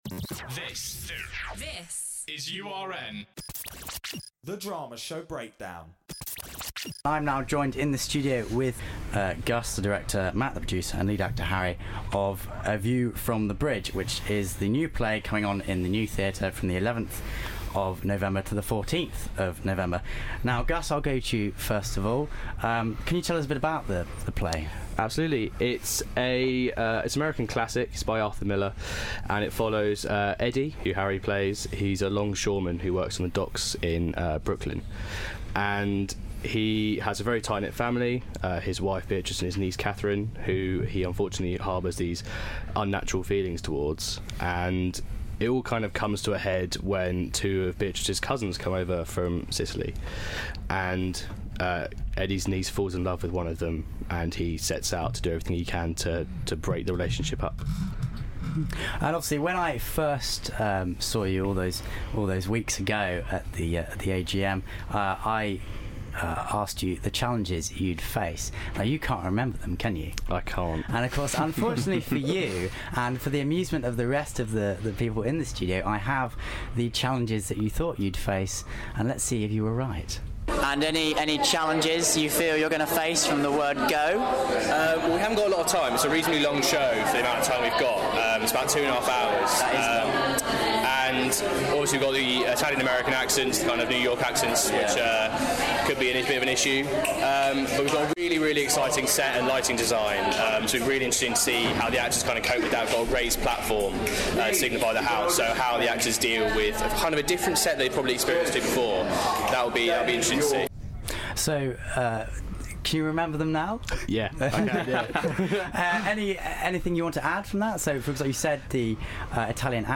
A View From the Bridge Interview